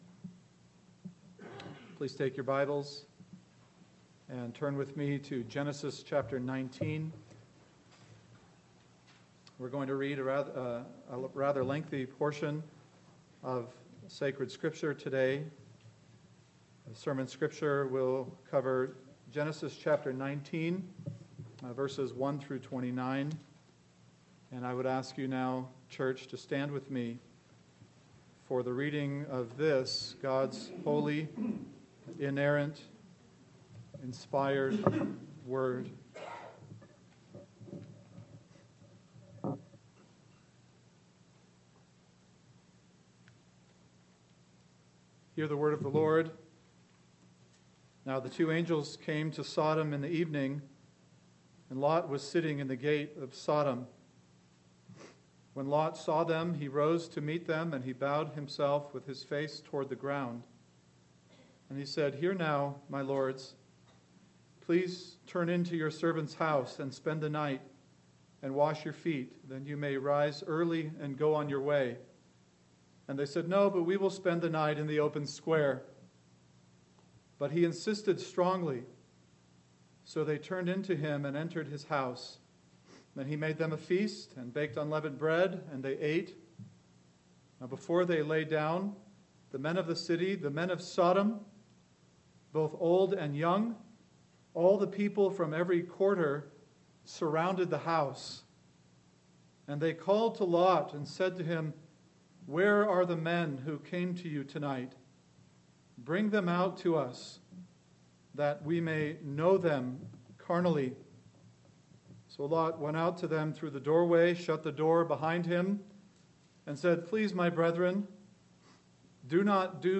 AM Sermon – 03/05/2017 – Genesis 19:1-29 – Escape For Your Life!